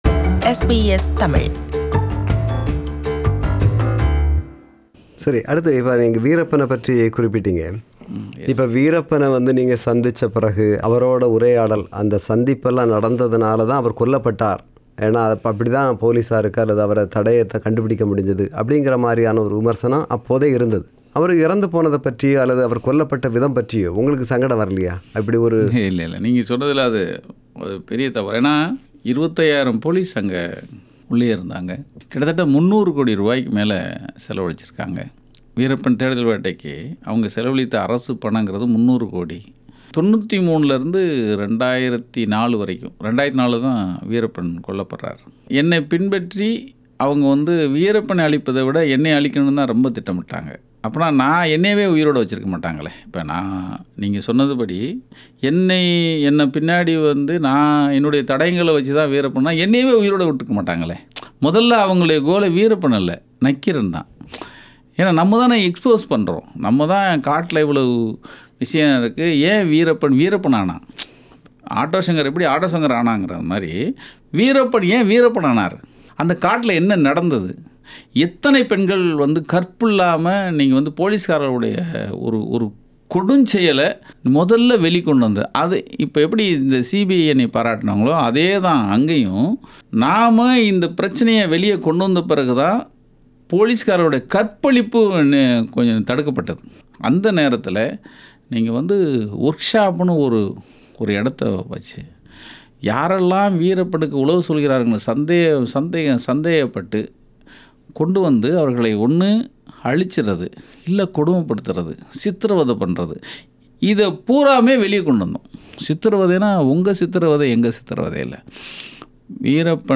Interview with Nakkheeran Gopal – Part 2